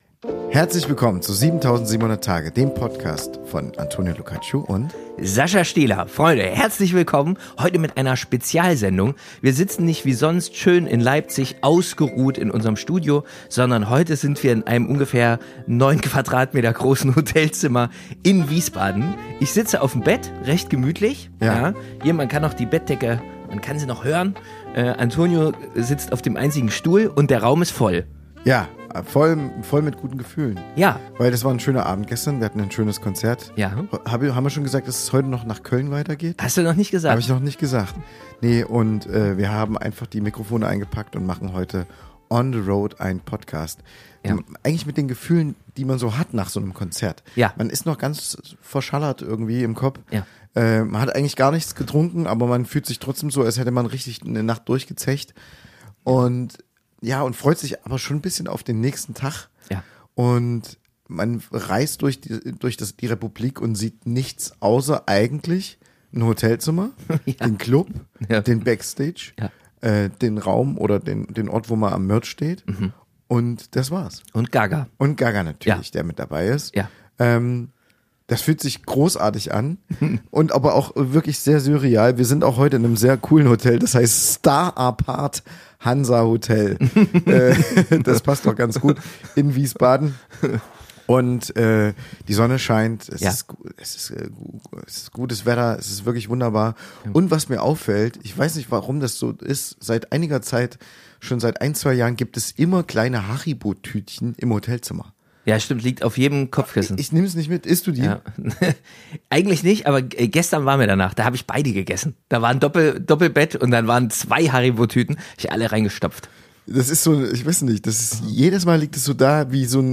Beschreibung vor 4 Monaten Im Bett liegend, eingeklemmt zwischen Koffer und Bildern von Leonardo DiCaprio, melden wir uns dieses Mal aus unserem 9-m²-Hotelzimmer in Wiesbaden – live aus dem Herzen unseres Touralltags. Nach einer kurzen Analyse unserer letzten Konzerte verwandelt sich dieser Podcast unerwartet in einen Beziehungsratgeber für Fortgeschrittene.
Am Ende wird das kleine Hotelzimmer, früh am Morgen und mit zu wenig Schlaf, tatsächlich zu einem philosophischen Mini-Tempel, in dem zwei Duo-Denker über das Leben, die Liebe und die Kunst der Freundschaft brüten.